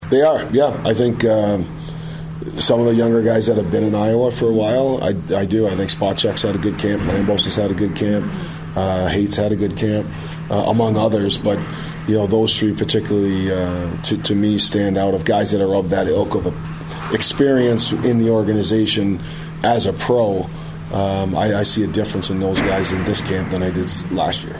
Wild head coach John Hynes on if the young guys are making an impression at this point in camp.